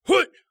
ZS发力5.wav 0:00.00 0:00.52 ZS发力5.wav WAV · 45 KB · 單聲道 (1ch) 下载文件 本站所有音效均采用 CC0 授权 ，可免费用于商业与个人项目，无需署名。
人声采集素材/男3战士型/ZS发力5.wav